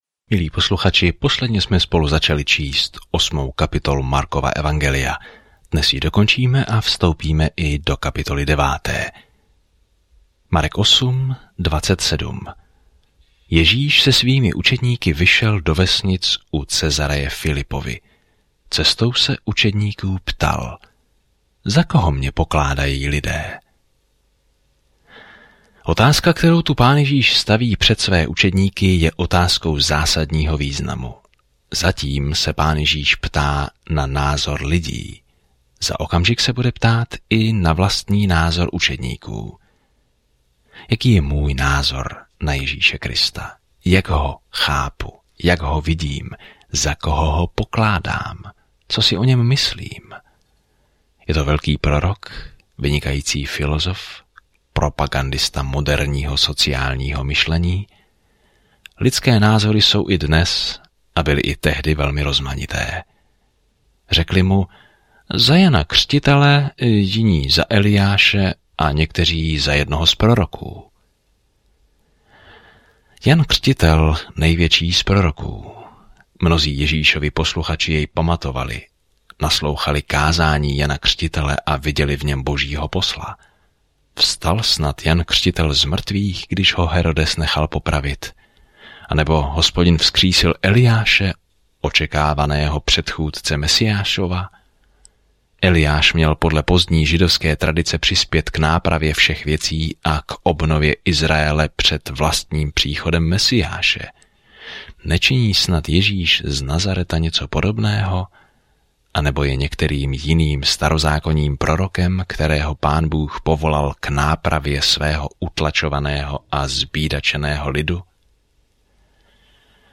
Písmo Marek 8:27-38 Marek 9:1-2 Den 17 Začít tento plán Den 19 O tomto plánu Markovo kratší evangelium popisuje pozemskou službu Ježíše Krista jako trpícího Služebníka a Syna člověka. Denně procházejte Markem a poslouchejte audiostudii a čtěte vybrané verše z Božího slova.